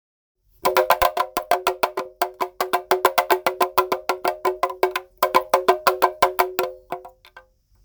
ティクタクドラム 動物柄
キリン・シマウマ柄のアフリカンなでんでん太鼓です。
素材： 木 皮 ブリキ